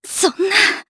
Yanne-Vox_Dead_jp.wav